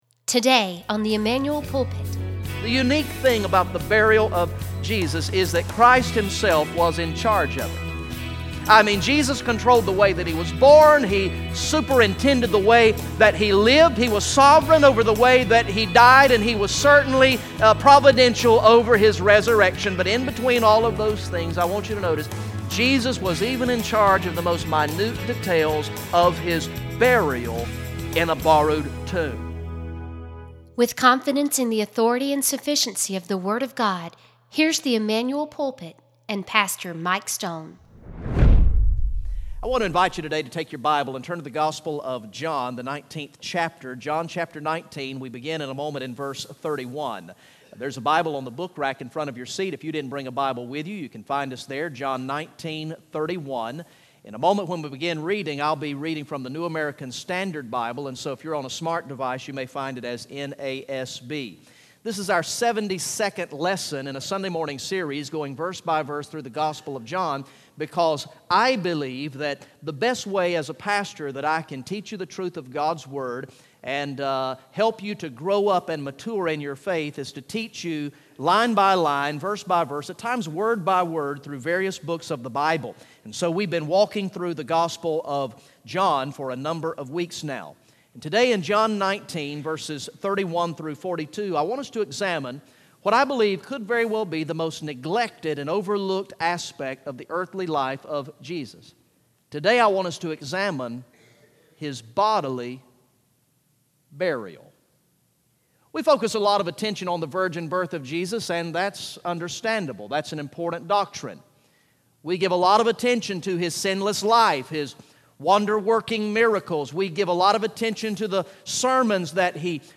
Message #72 from the sermon series through the gospel of John entitled "I Believe" Recorded in the morning worship service on Sunday, September 18, 2016